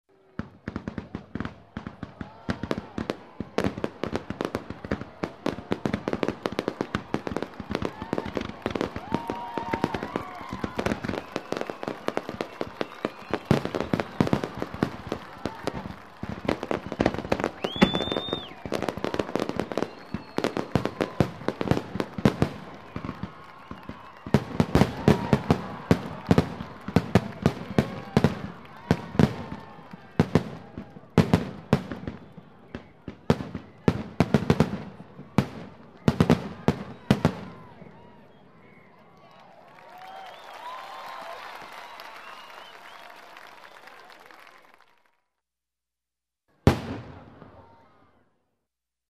Звуки фейерверка, салюта
На этой странице собраны разнообразные звуки фейерверков и салютов: от одиночных хлопков до продолжительных залпов.